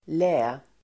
Ladda ner uttalet
lä substantiv, lee , shelter Uttal: [lä:] Synonymer: vindskydd Definition: (sida där man har) skydd mot vinden Idiom: ligga i lä ("vara underlägsen") (put in the shade, (be left in the dust [US]) ("be inferior"))